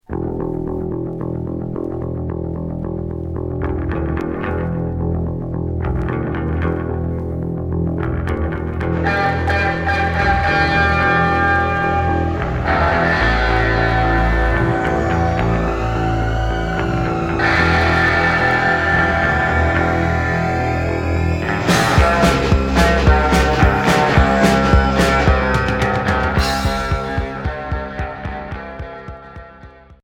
Punk rock new wave Troisième 45t retour à l'accueil